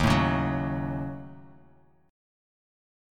D#m7b5 Chord
Listen to D#m7b5 strummed